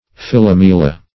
Philomela \Phil`o*me"la\, n.